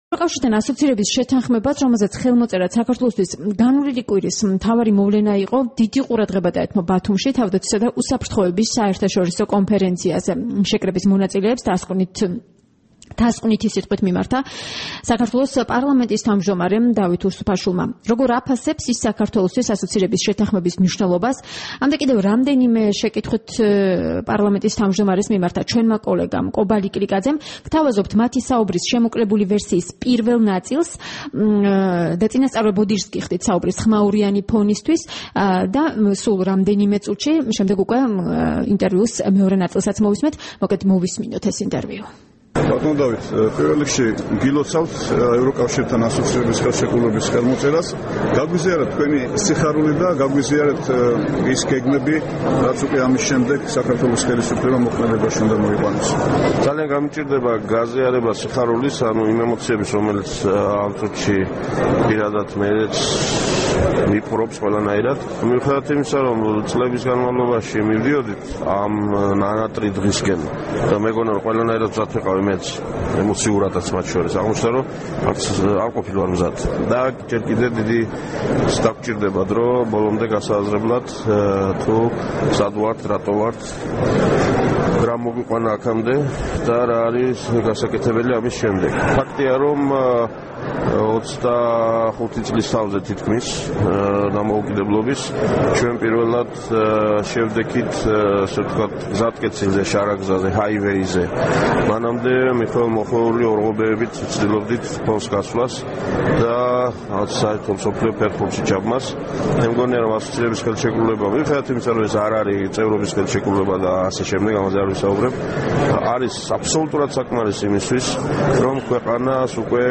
საქართველოს პარლამენტის თავმჯდომარე ევროკავშირთან ასოცირების შეთანხმებისა და ნატოსთან დაახლოების მნიშვნელობაზე ესაუბრა რადიო თავისუფლებას.
საუბარი დავით უსუფაშვილთან